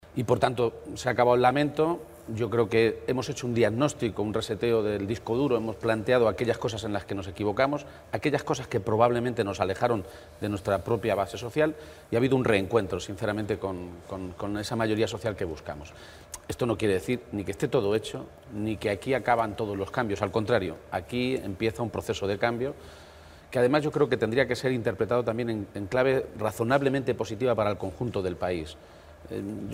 García-Page se pronunciaba de esta manera durante una entrevista en el programa Espejo Público de Antena 3 Televisión, en el que aseguraba que “los socialistas volvemos a decirle con claridad a los españoles lo que pueden esperar de nosotros en materia fiscal, de empleo y de defensa del Estado del Bienestar frente al desmantelamiento de los servicios públicos esenciales que están llevando a cabo los Gobiernos del PP en la nación y en la mayoría de las comunidades autónomas”.
Page-entrevista_Antena_3-1.mp3